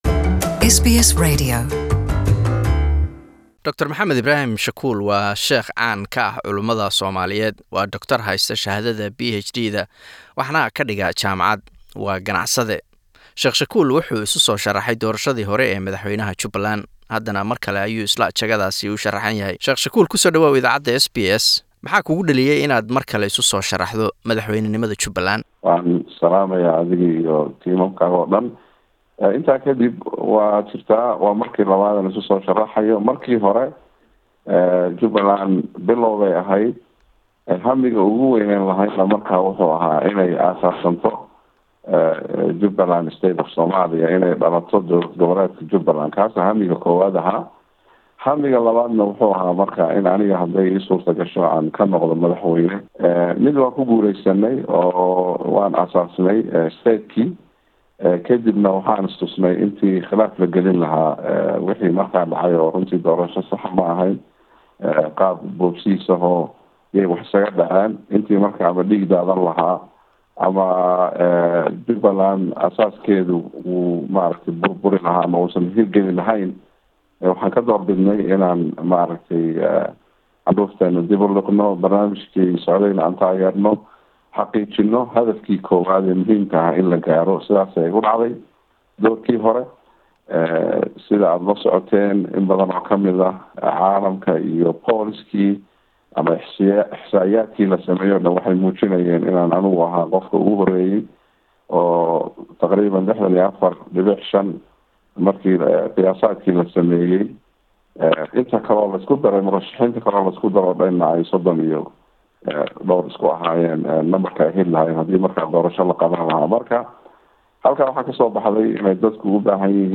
Qaybtii labaad ee waraysiga